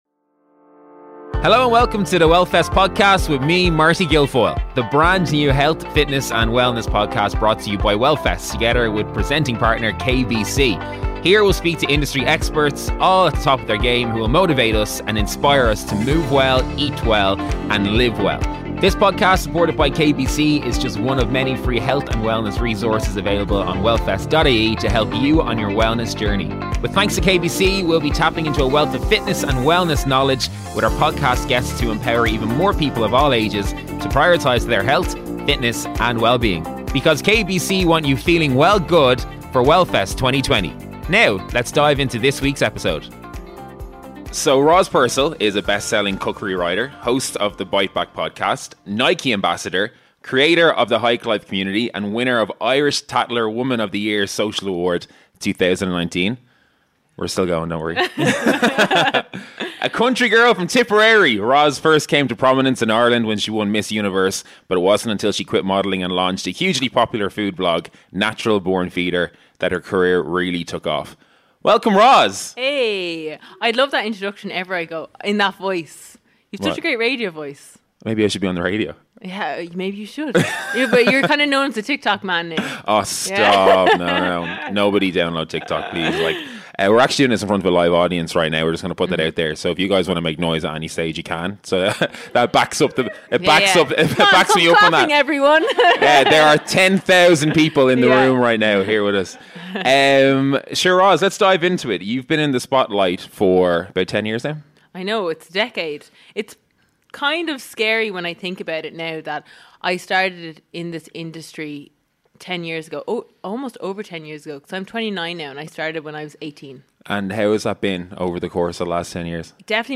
The first episode in the series was recorded in front of a live audience at the podcast launch event.